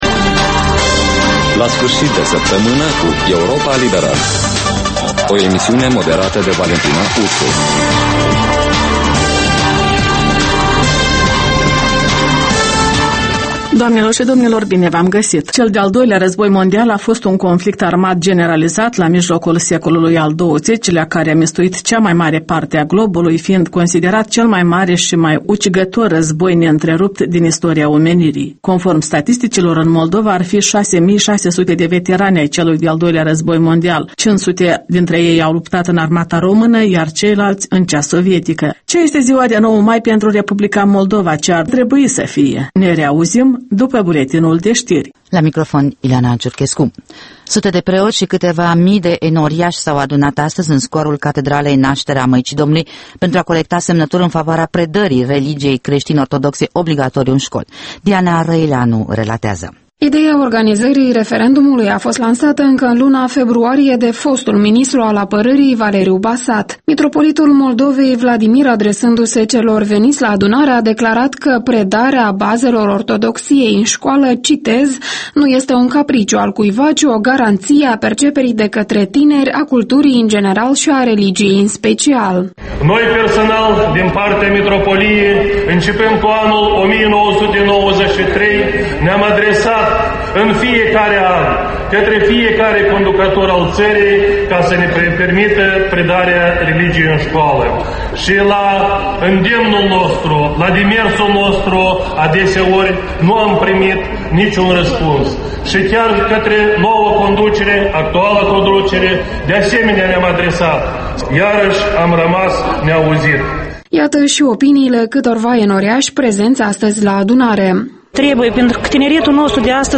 O emisiune în reluare cu un buletin de ştiri actualizat, emisiunea se poate asculta şi pe unde scurte